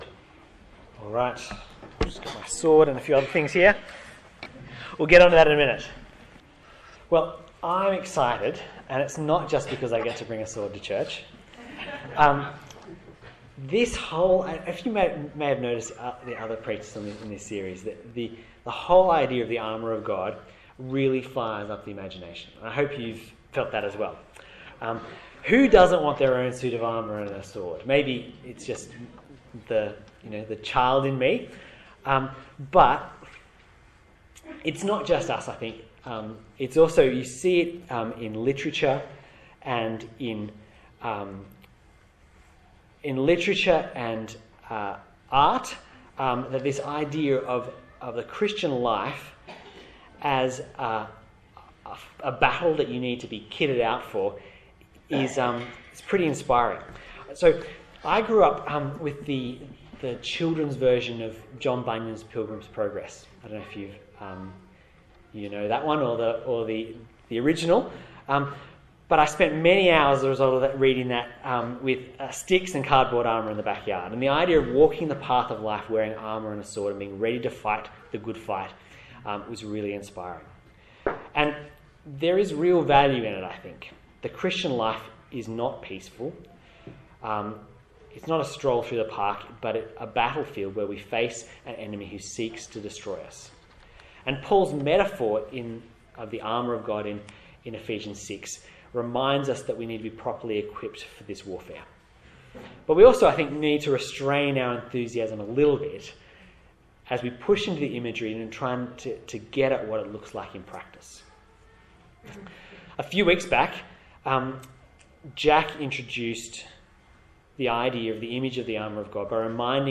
Passage: Matthew 4:1-11, Ephesians 6:11-20 Service Type: Morning Service